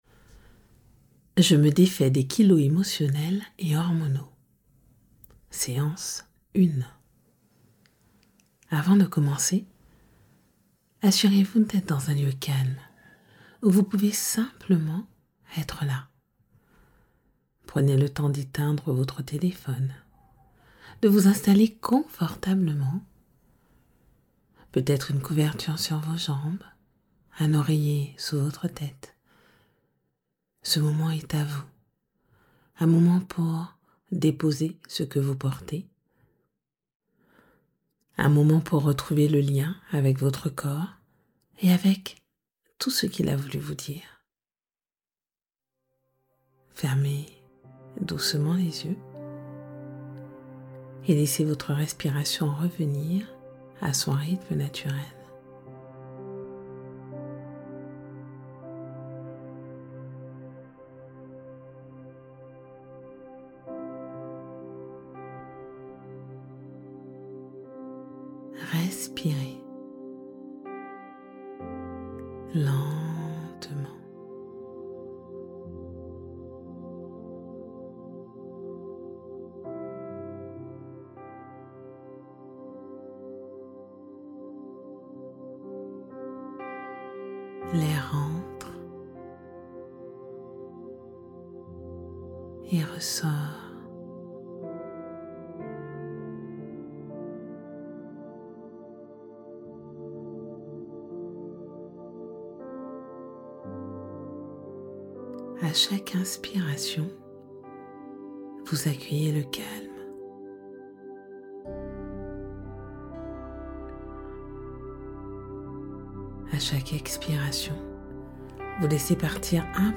Bienvenue dans cette séance d’hypnose.